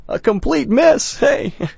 gutterball-3/Gutterball 3/Commentators/Bill/b_completemiss.wav at d85c54a4fee968805d299a4c517f7bf9c071d4b9
b_completemiss.wav